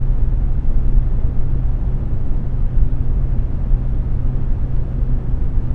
computer_ambience.wav